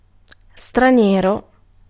Ääntäminen
Vaihtoehtoiset kirjoitusmuodot (rikkinäinen englanti) furrin (vanhentunut) forein Synonyymit overseas alien international extraneous fremd Ääntäminen UK : IPA : /ˈfɒɹ.ən/ GenAm: IPA : /ˈfɔɹ.ən/ US : IPA : [ˈfɔɹ.ən] Tuntematon aksentti: IPA : /ˈfɔɹn/